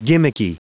Prononciation du mot : gimmicky
gimmicky.wav